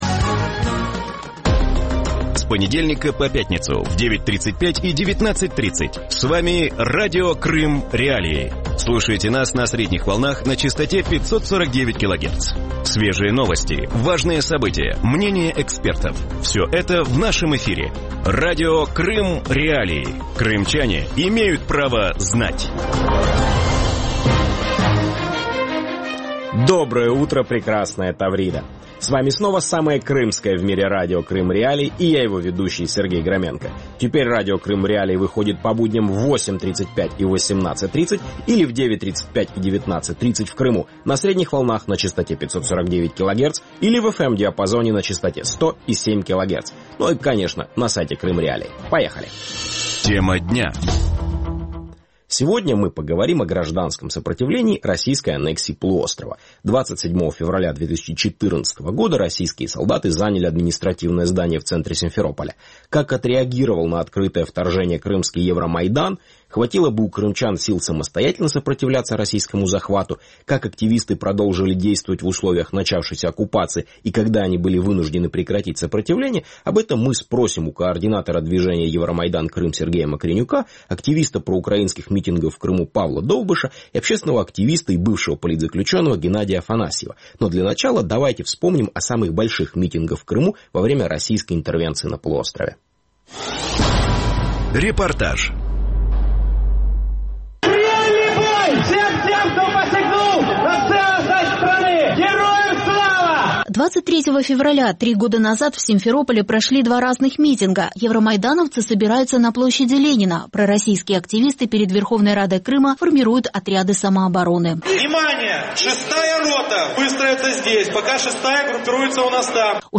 У ранковому ефірі Радіо Крим.Реалії говорять про громадянський опір анексії півострова. 27 лютого 2014 року російські солдати зайняли адміністративні будівлі в центрі Сімферополя. Як відреагував на відкрите вторгнення кримський євромайдан? Як активісти продовжували діяти в умовах розпочатої окупації?